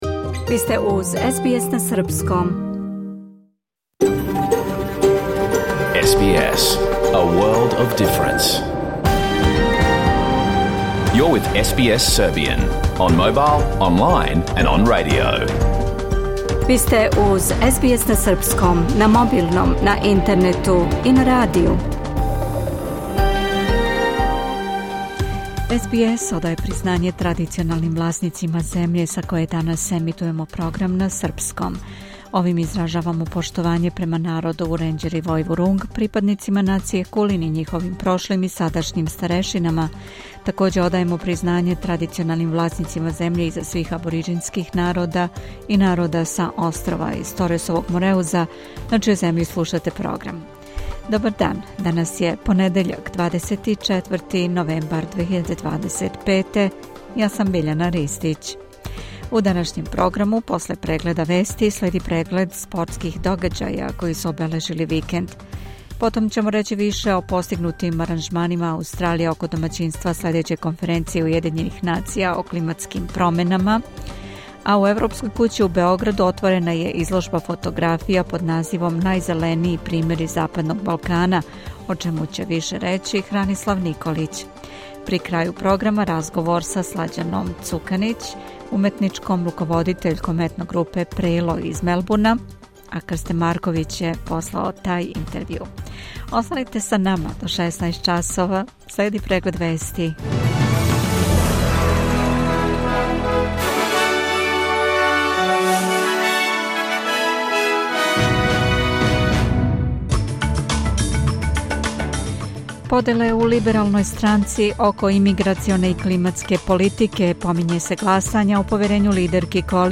Програм емитован уживо 24. новембра 2025. године